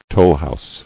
[toll·house]